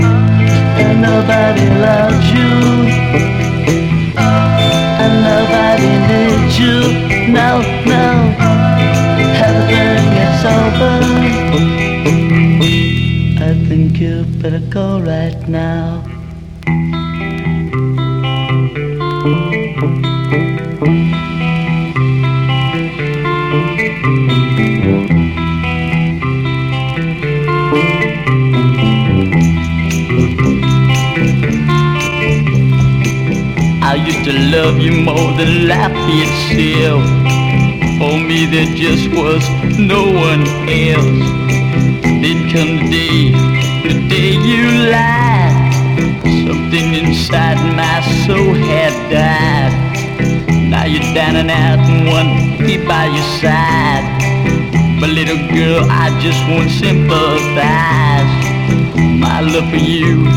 ROCK / 60'S / GARAGE ROCK / GARAGE PSYCHE / PSYCHEDELIC ROCK